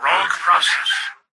"Rogue process" excerpt of the reversed speech found in the Halo 3 Terminals.
H3_tvox_no7_rogueprocess_(unreversed_trimmed).mp3